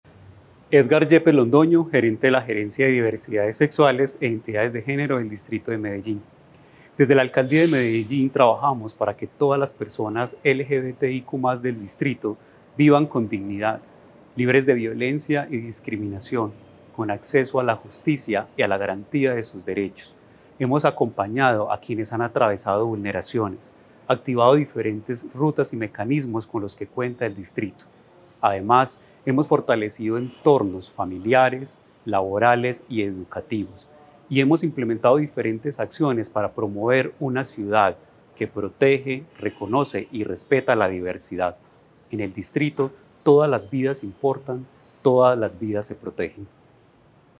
Audio Declaraciones del gerente de Diversidades Sexuales e Identidades de Género, Edgar Yepes Londoño La Alcaldía de Medellín, a través de sus diferentes dependencias, reafirma su compromiso con la vida, la dignidad y los derechos de las personas LGBTIQ+.
Audio-Declaraciones-del-gerente-de-Diversidades-Sexuales-e-Identidades-de-Genero-Edgar-Yepes-Londono.mp3